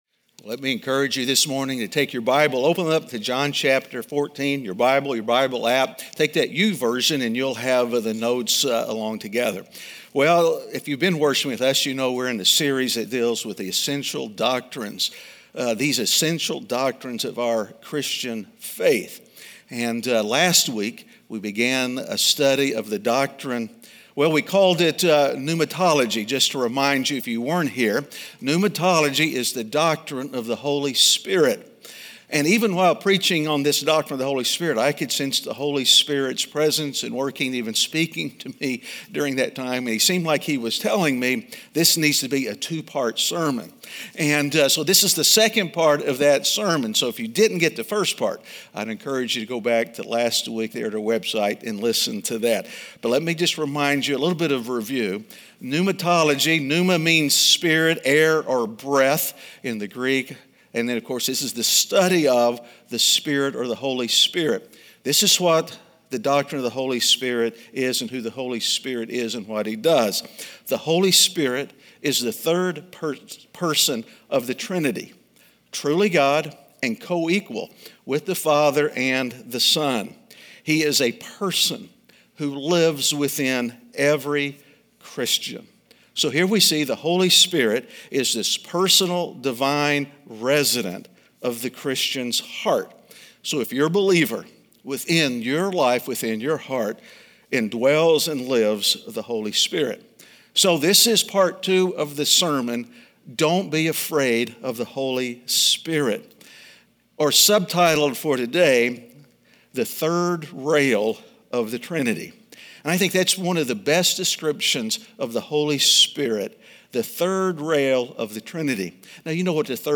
Is There A Doctrine In The House? (Week 7) - Sermon.mp3